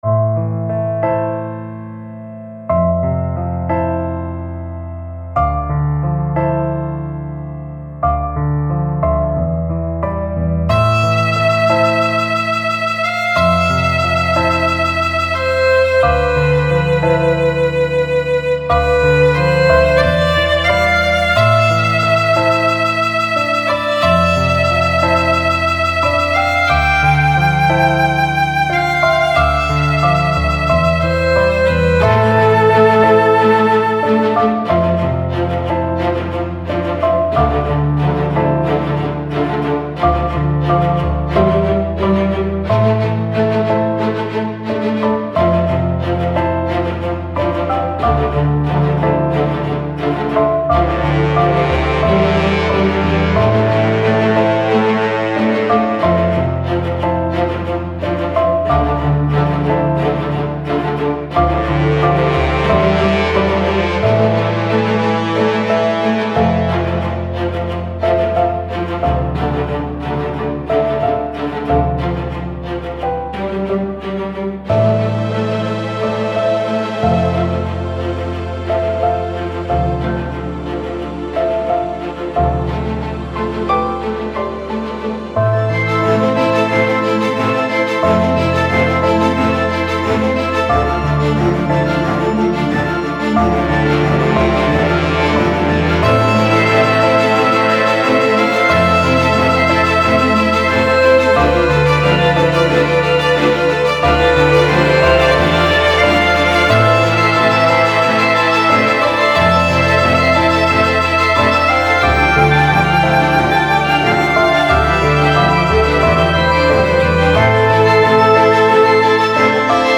/ New age